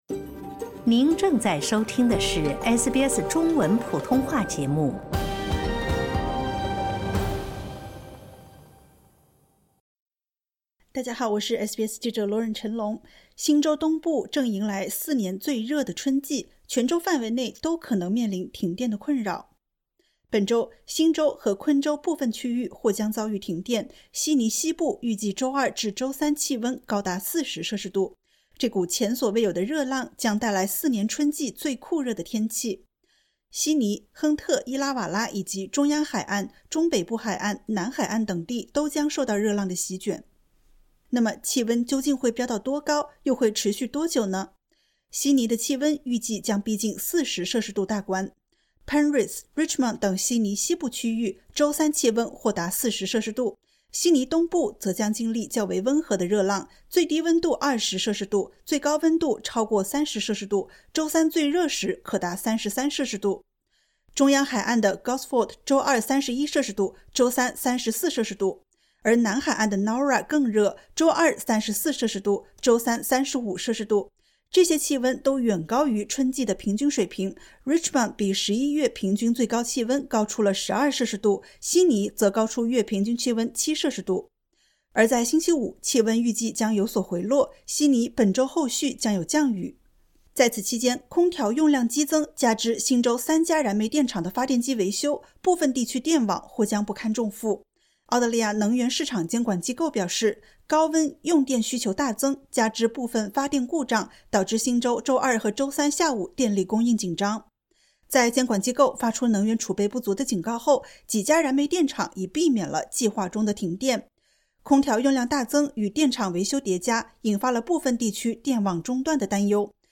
新南威尔士州东部迎来四年来最热的春季，全州范围内都可能面临停电的困扰。点击 ▶ 收听完整报道。